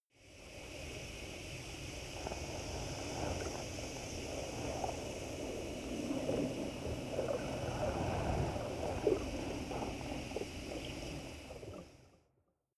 Royalty free sounds: Horror